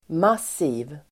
Uttal: [mas'i:v]